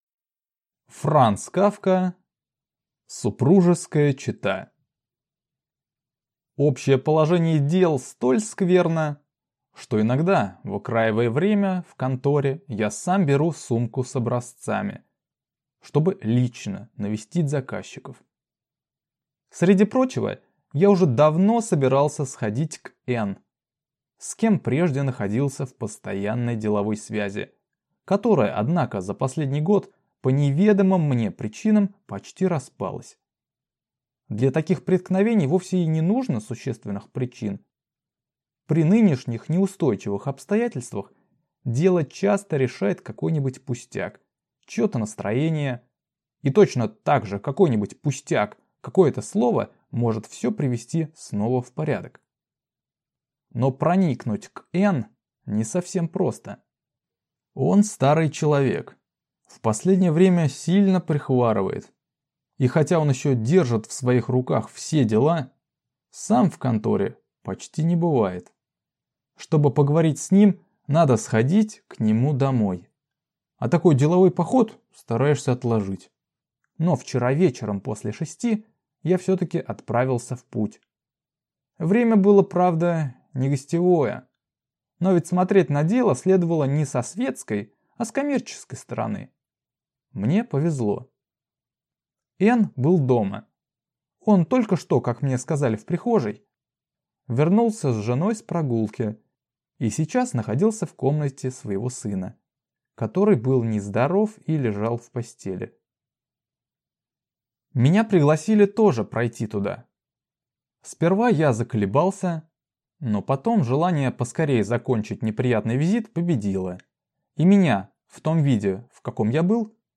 Аудиокнига Супружеская чета | Библиотека аудиокниг
Aудиокнига Супружеская чета Автор Франц Кафка